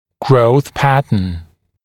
[grəuθ ‘pæt(ə)n][гроус ‘пэт(э)н]тип роста, модель роста